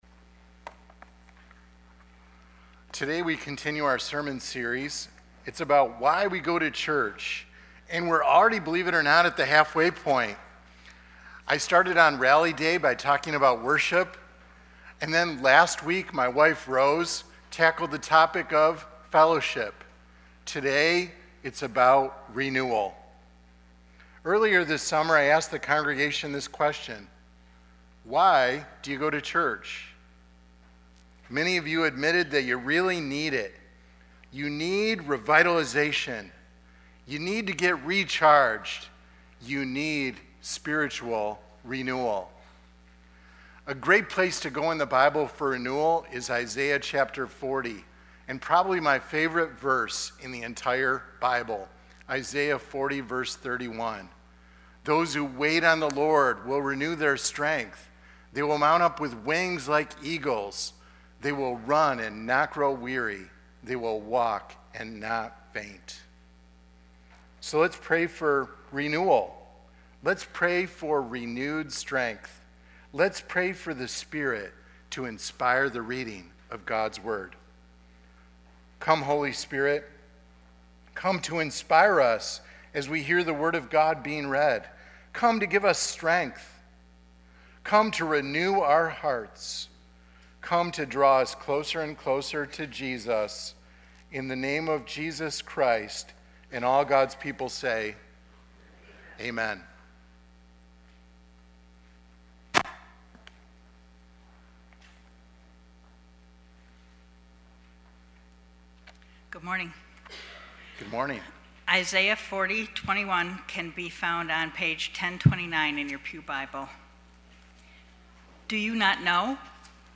Lakeview Sermon Podcast